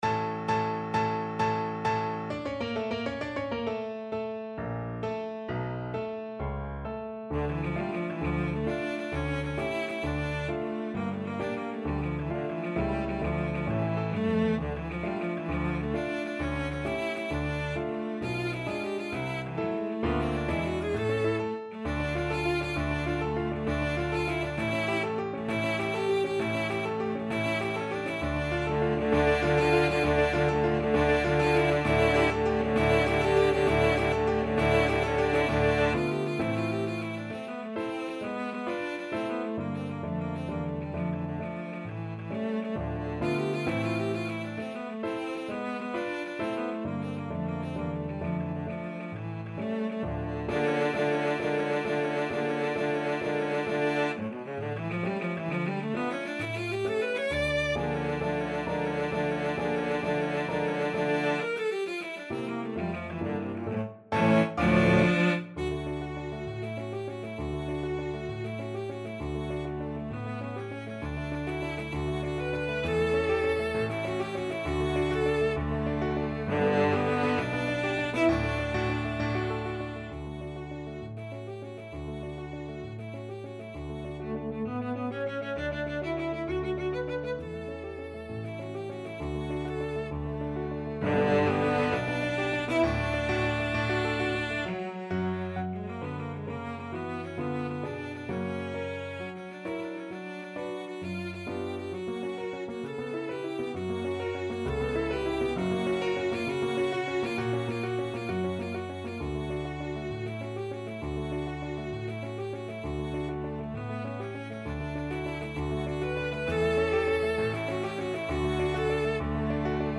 6/8 (View more 6/8 Music)
Classical (View more Classical Cello Music)